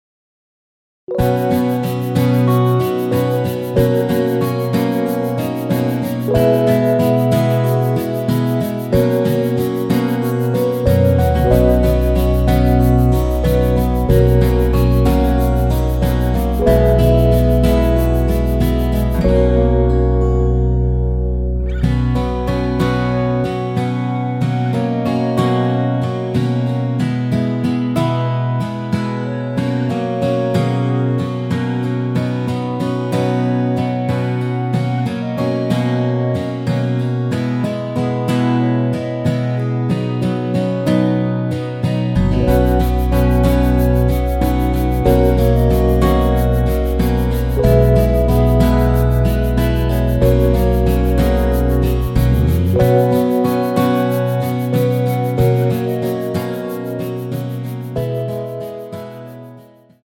원키에서(-2)내린 멜로디 포함된 MR입니다.
◈ 곡명 옆 (-1)은 반음 내림, (+1)은 반음 올림 입니다.
앞부분30초, 뒷부분30초씩 편집해서 올려 드리고 있습니다.